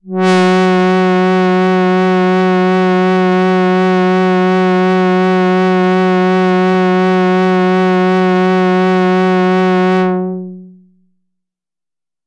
标签： F4 midinote66 RolandJX3P synthesizer singlenote multisample
声道立体声